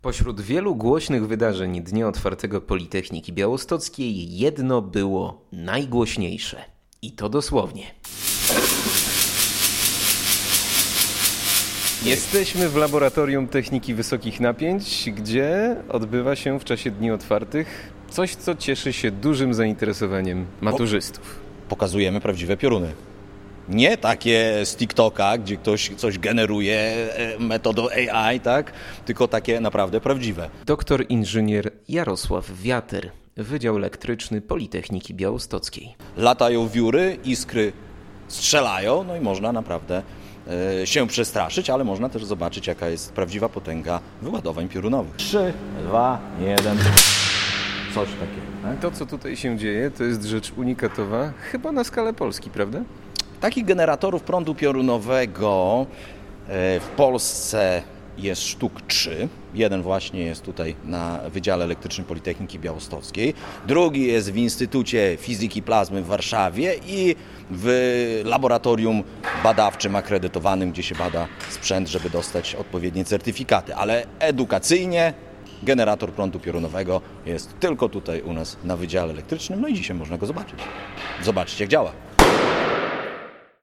Odwiedziliśmy także laboratorium Techniki Wysokich Napięć, gdzie strzelały prawdziwe pioruny!